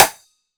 Glassy Rim.wav